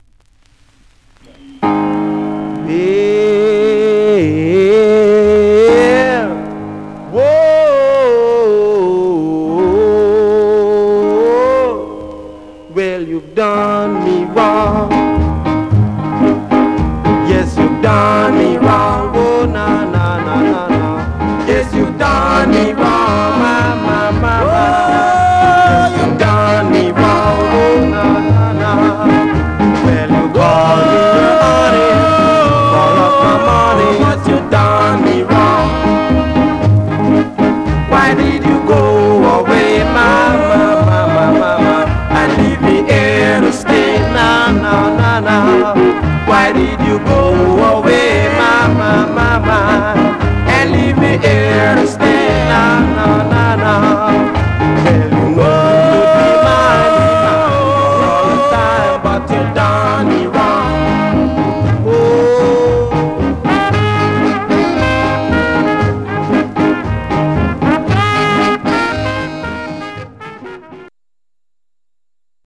Mono
Rythm and Blues Condition